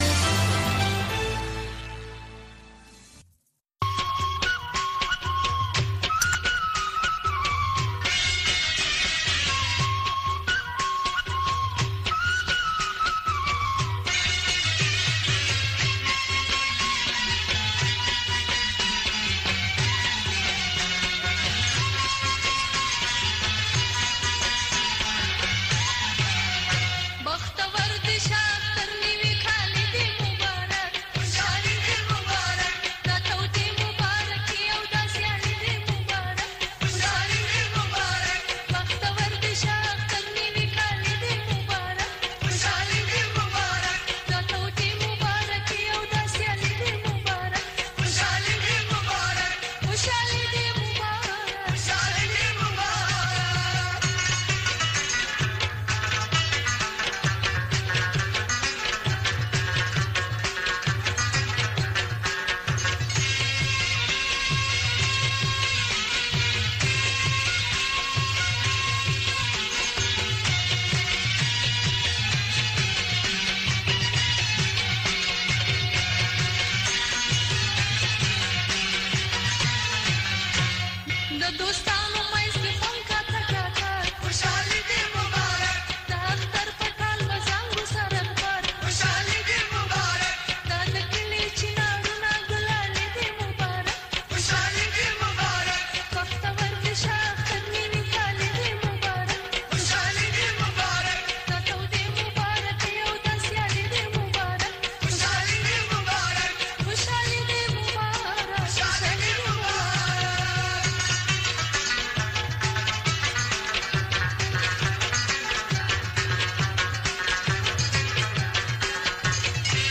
په دغې خپرونه کې د روانو چارو پر مهمو مسایلو باندې له اوریدونکو او میلمنو سره خبرې کیږي.